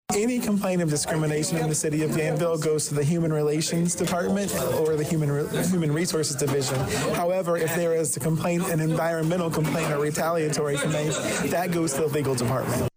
Mayor Williams also pointed out that if a tenant is claiming they are being discriminated against, the complaint will go to Human Resources.